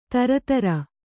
♪ tara tara